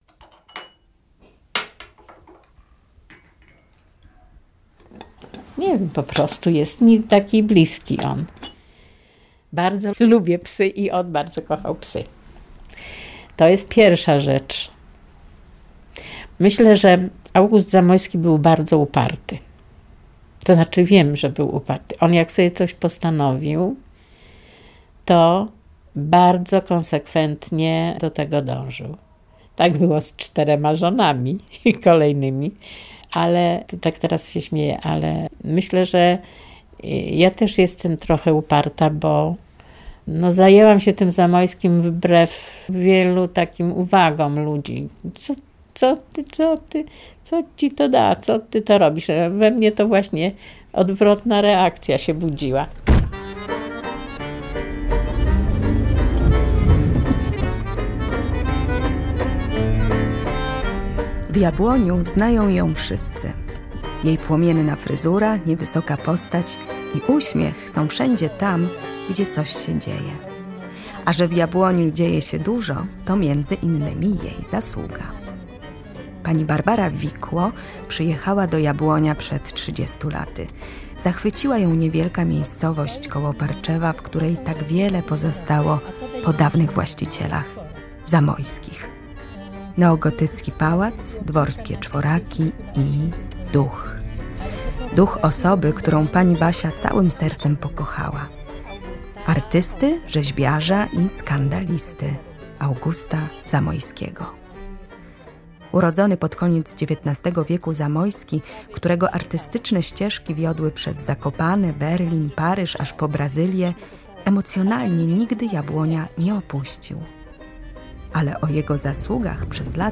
Tagi: reportaż